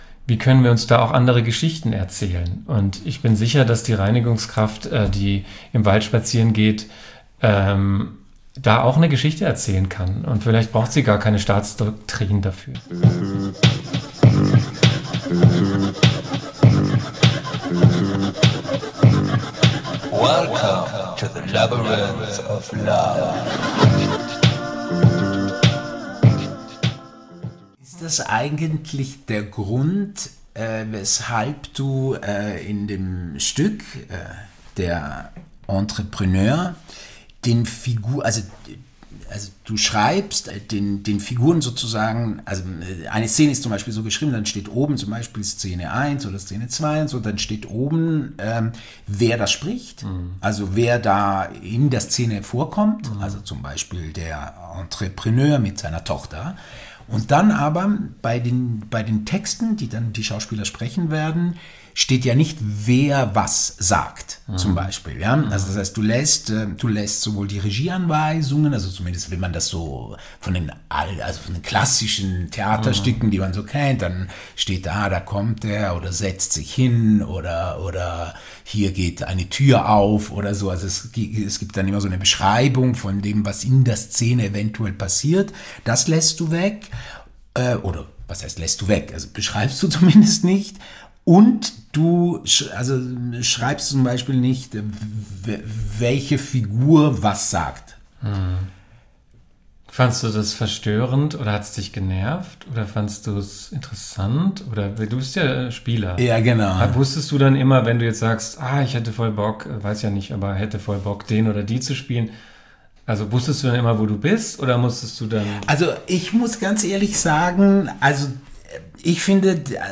Ihr Podcast ist ein Talk unter Freunden.